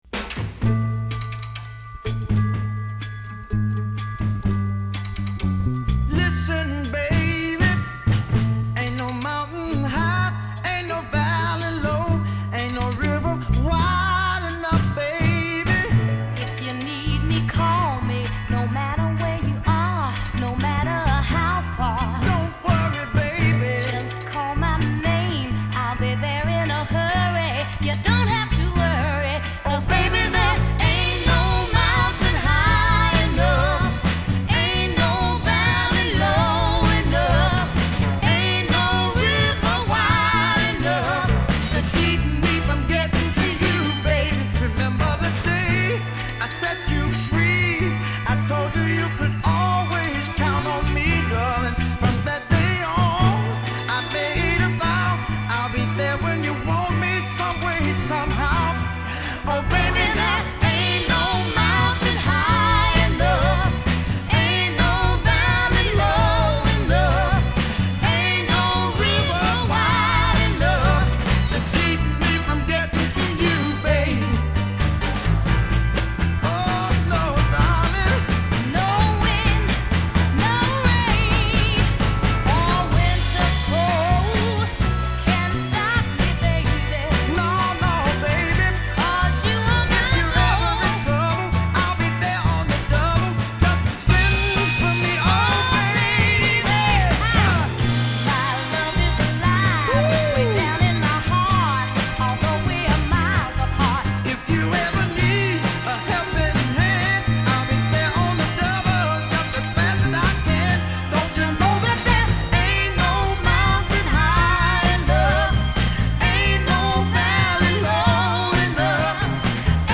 Für den kaputten Sound habe ich die Saiten gedämpft: